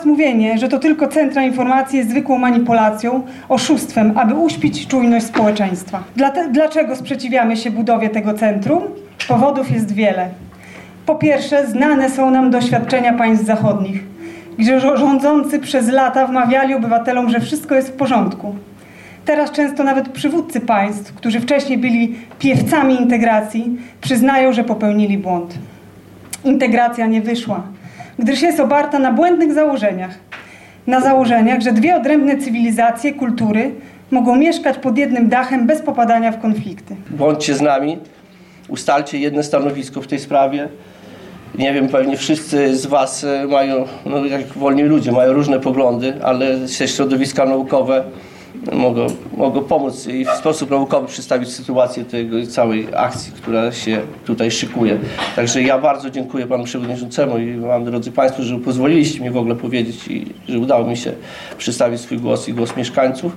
Na sesję Rady Miejskiej poświęconą między innymi stanowisku przybyła grupa suwalczan, która dała wyraz swojego sprzeciwu wobec powołania centrum.